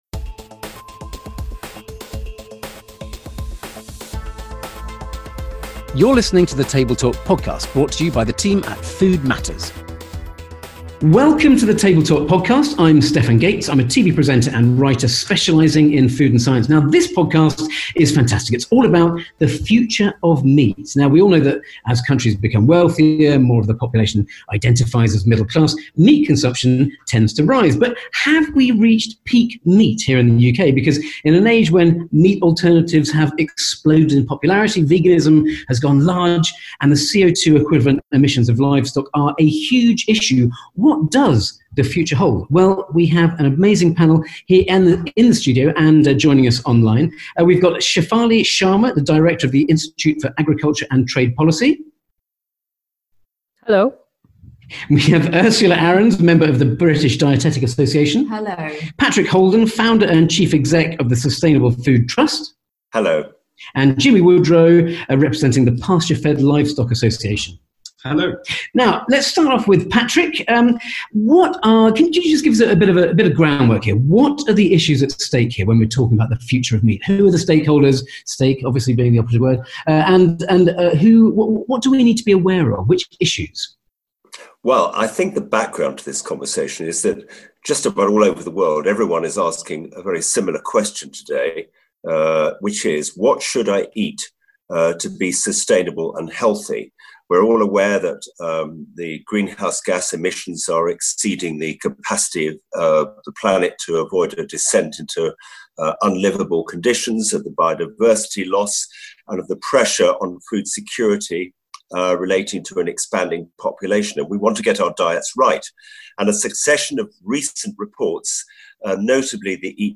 On this Table Talk Podcast, recorded back in March 2020 as COVID-19 lockdowns were just taking shape in the UK, our panel argues that perhaps we should change the conversation around meat and future diets.